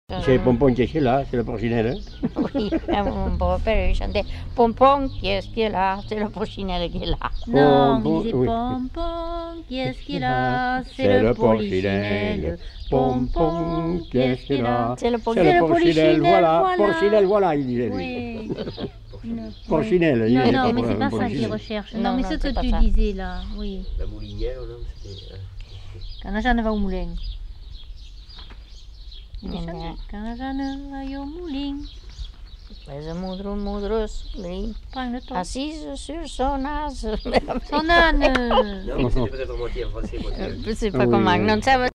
Lieu : Ruffiac
Genre : chant
Effectif : 1
Type de voix : voix de femme
Production du son : chanté
Notes consultables : Bribes d'un chant en fin de séquence.